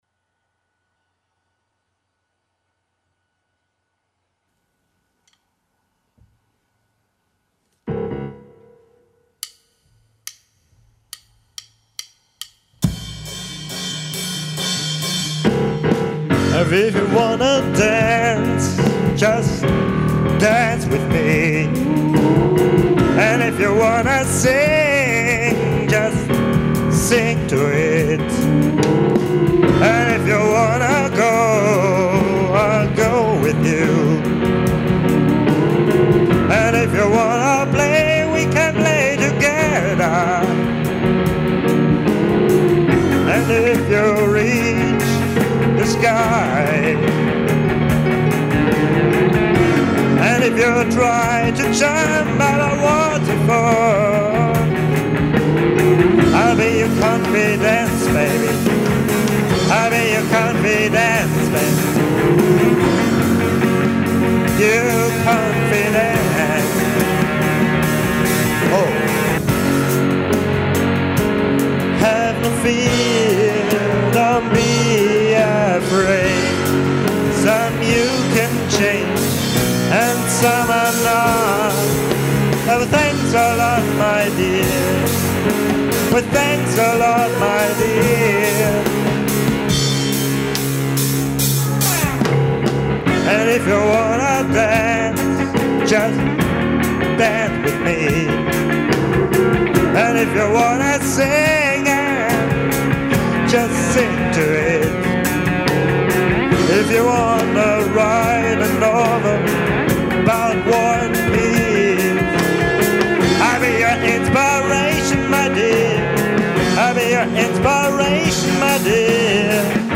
voc
gui